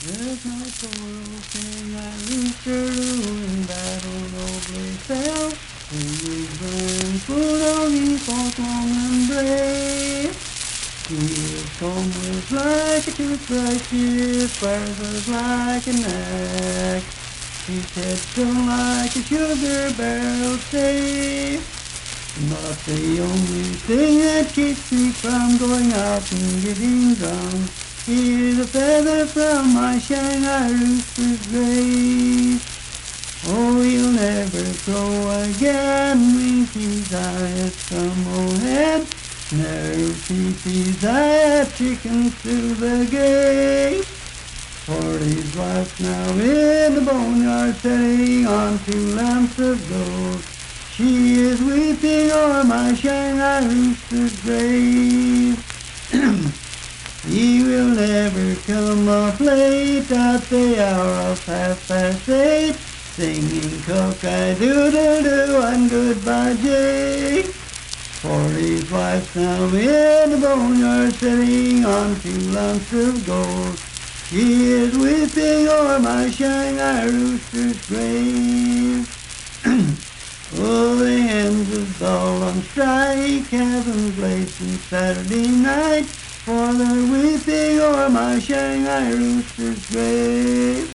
Unaccompanied vocal music
Verse-refrain 4(2-6). Performed in Dryfork, Randolph County, WV.
Miscellaneous--Musical
Voice (sung)